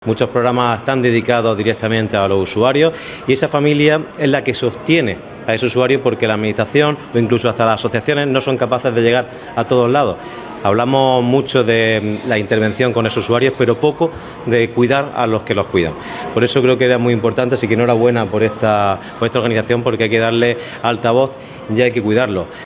FRANCISCO-BELLIDO-DELEGADO-INCLUSION-SOCIAL.wav